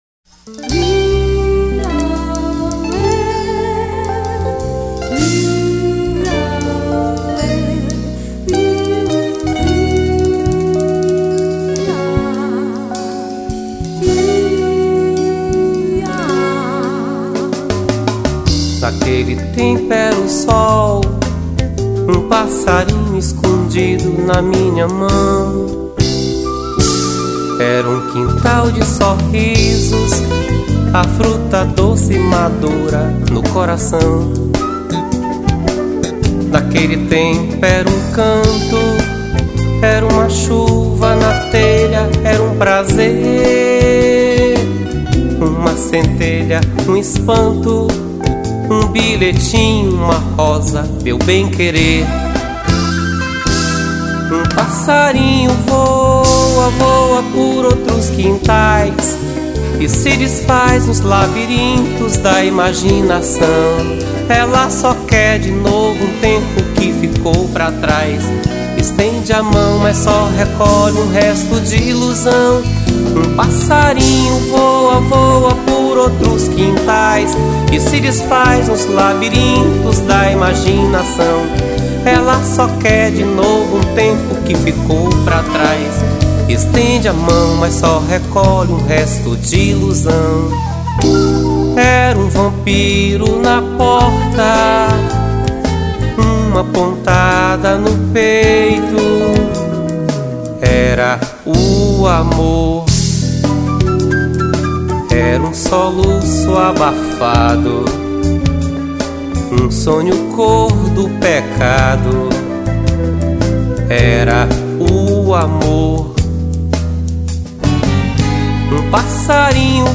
401   04:13:00   Faixa:     Canção